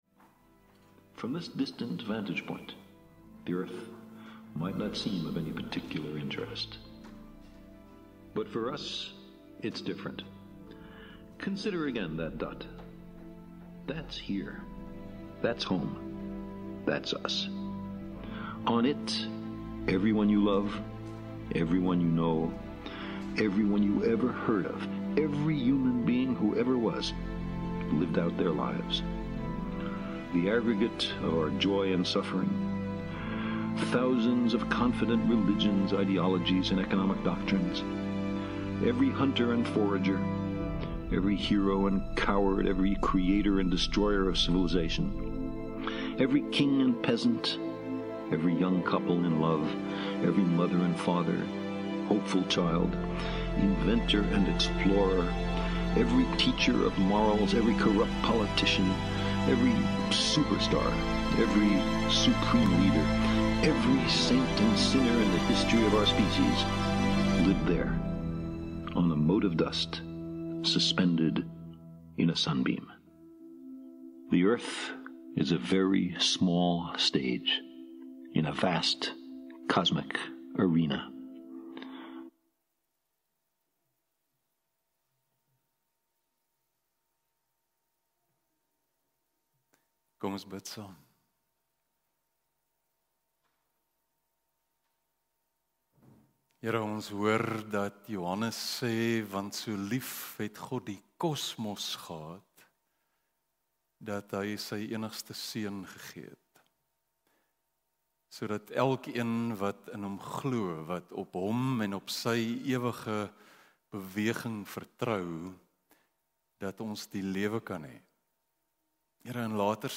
Stellenbosch Gemeente Preke 30 April 2023 || Storielyn - Waar is ons?